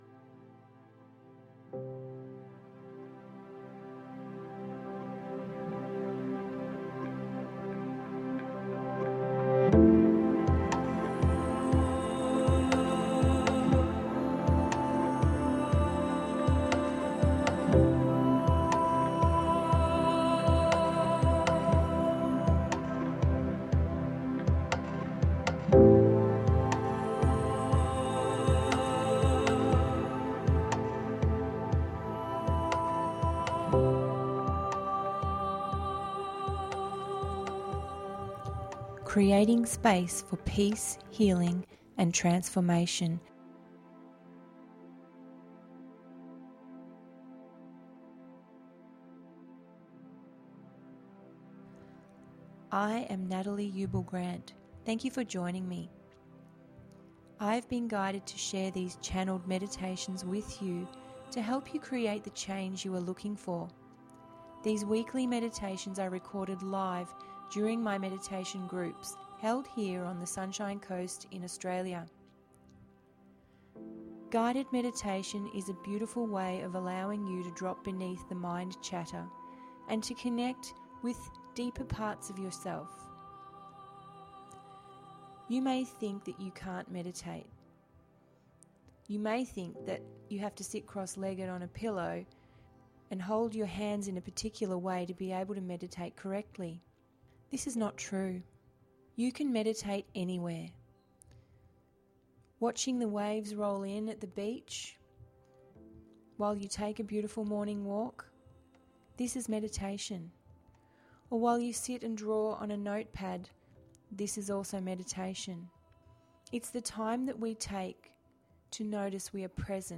We start today with the singing bowl.
Guided Meditation duration approx. 25 mins
111-expanded-frequency-guided-meditation.mp3